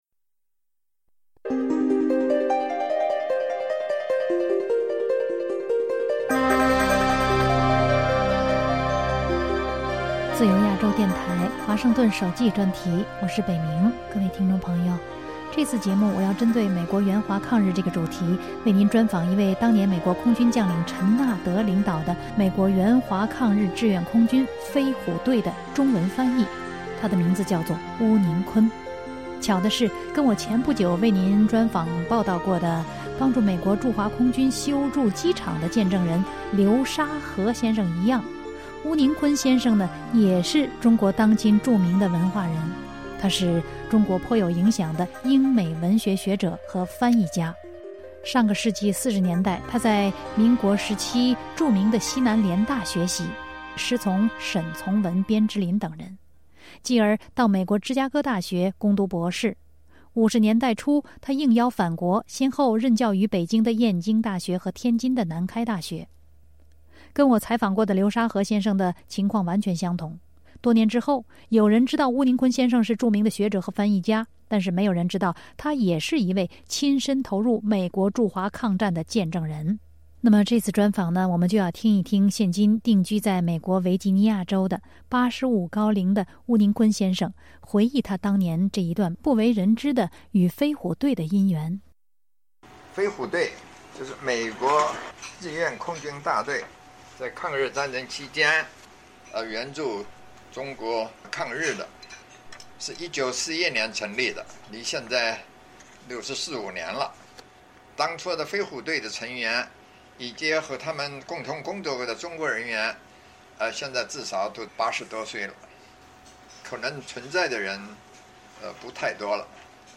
这次节目内容，由巫宁坤先生亲自口述，揭示了巫宁坤49后半生苦难的原因，提供了中美联手抗战被掩盖的细节。